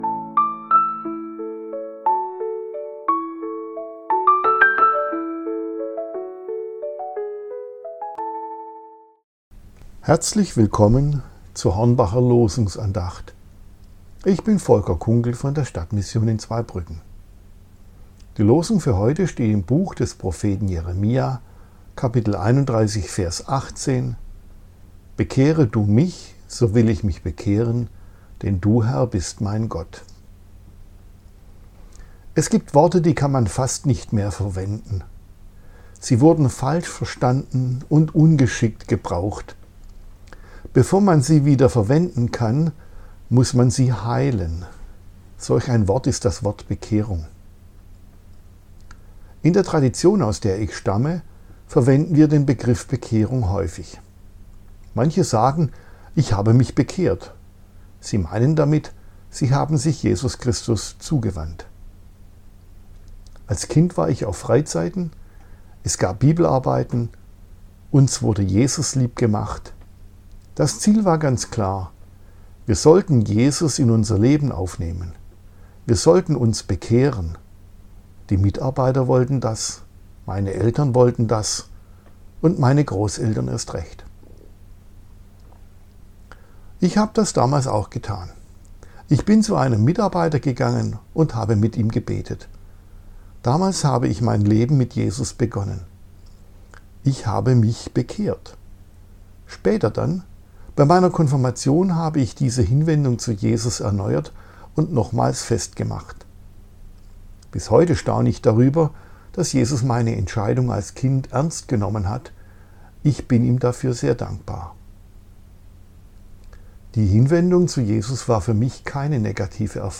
Losungsandacht für Dienstag, 02.12.2025 – Prot.